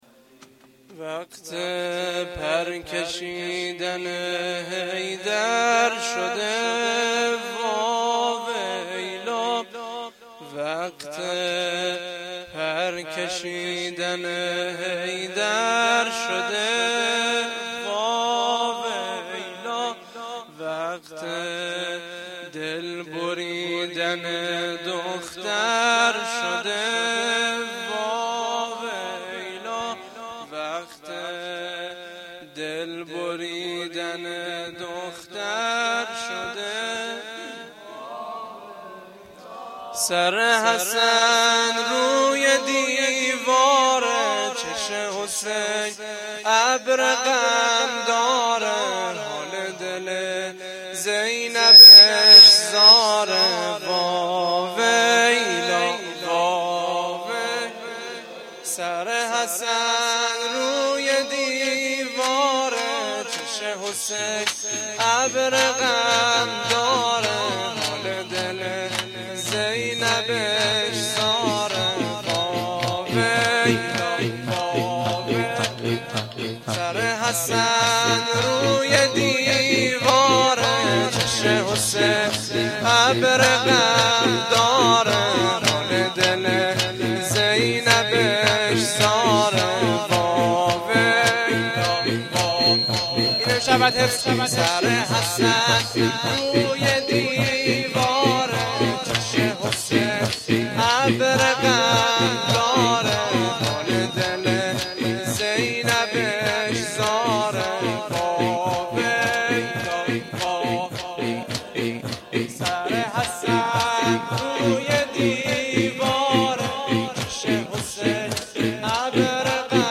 روضه العباس